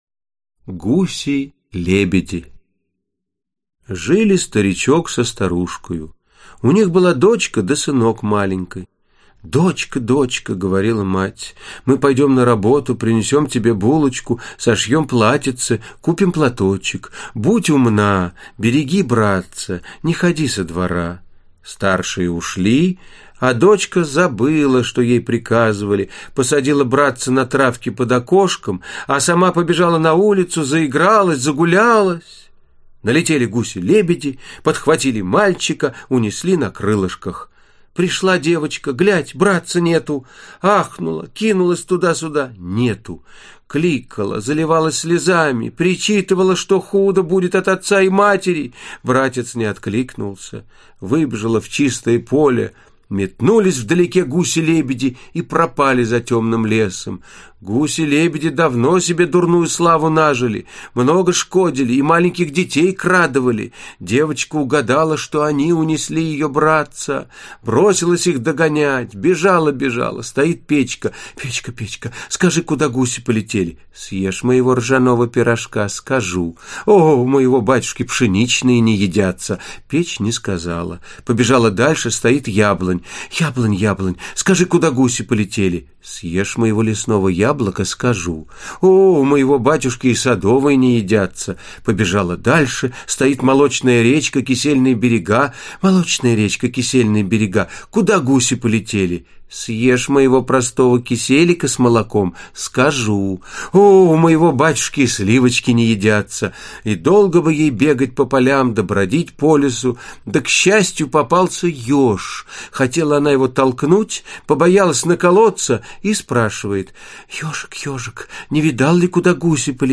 Аудиокнига Гуси-лебеди (сборник) | Библиотека аудиокниг
Aудиокнига Гуси-лебеди (сборник) Автор Народное творчество Читает аудиокнигу Вениамин Смехов.